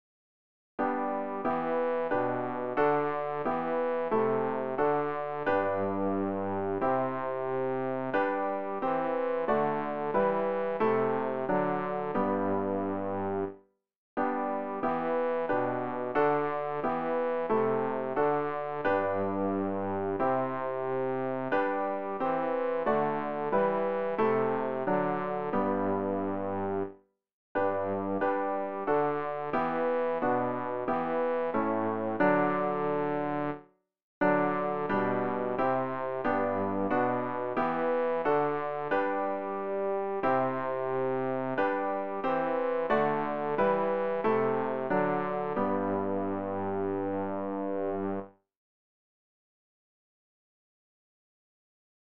rg-551-lobpreiset-all-zu-dieser-zeit-bass.mp3